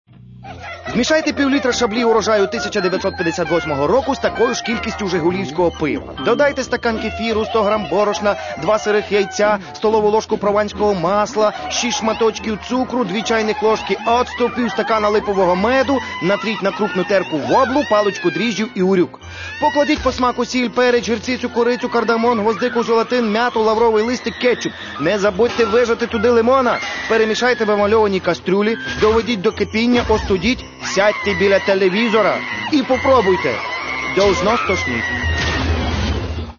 Каталог -> Рок та альтернатива -> Енергійний рок